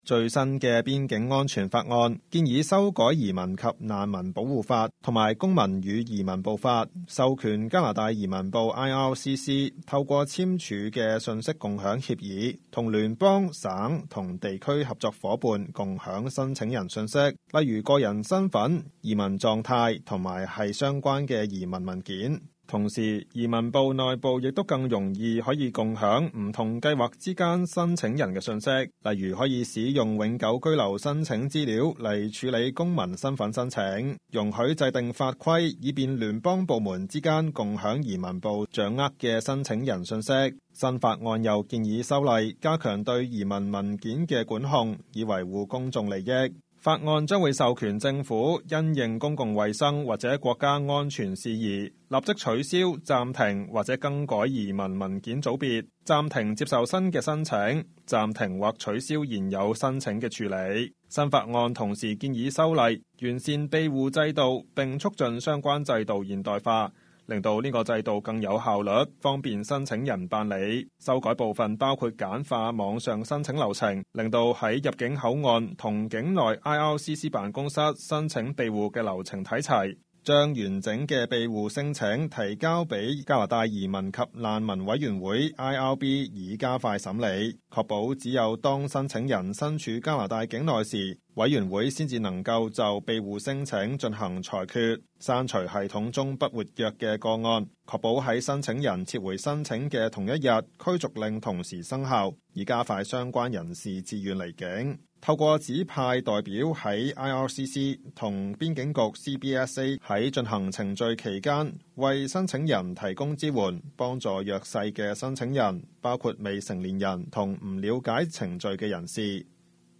news_clip_23638.mp3